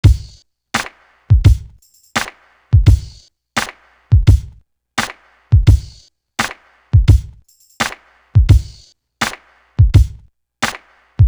Neighborhood Watch Drum.wav